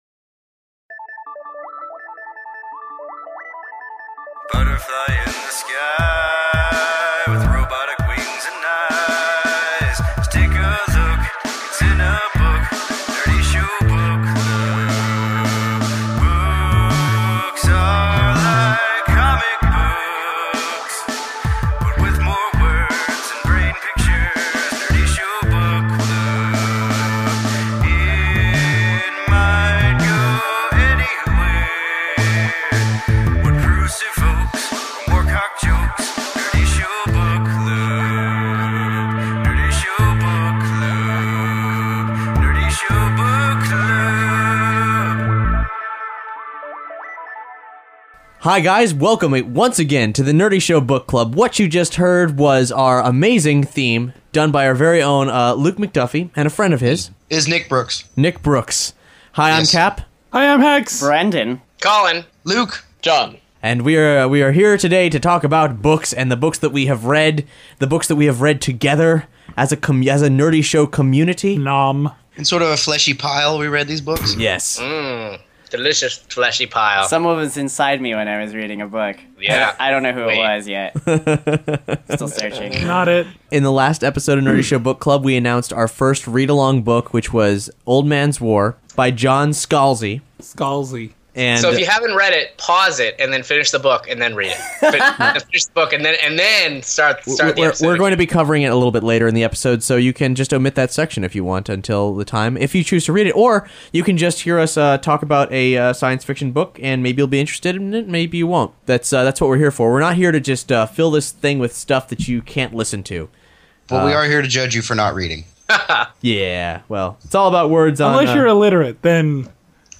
In this episode we debut our amazing new Book Club theme and crack open some sweet reads, new and old.